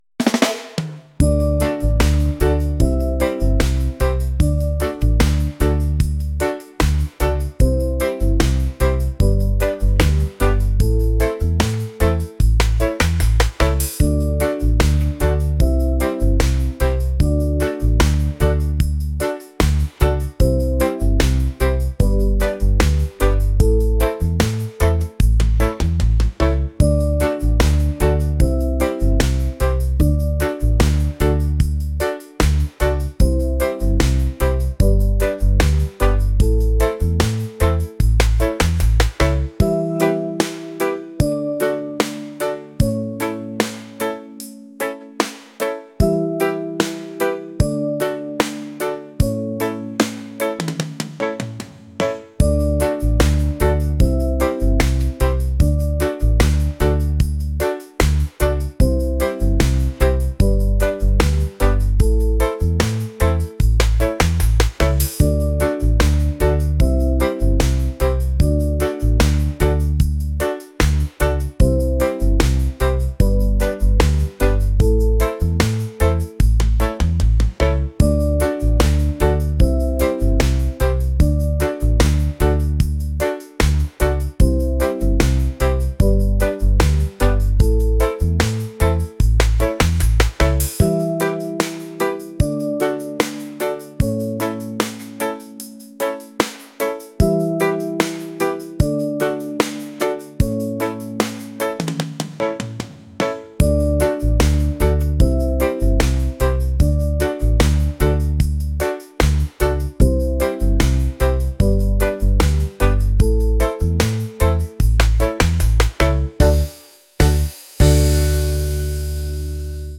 reggae | folk | lounge